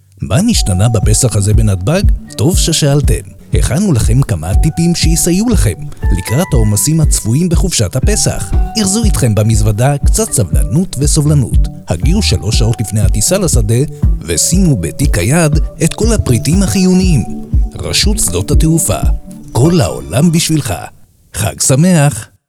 קריינות מקצועית ברמה אחרת